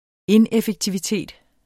Udtale [ ˈenεfəgtiviˌteˀd ]